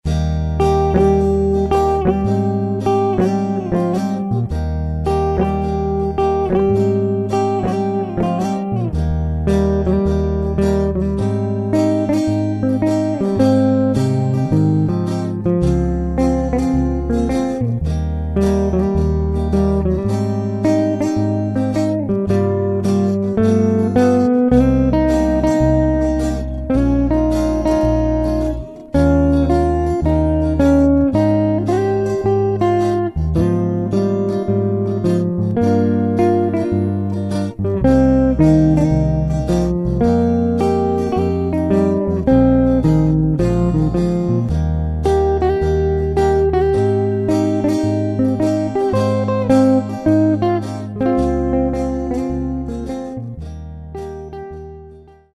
Chitarre e Basso